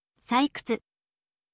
saikutsu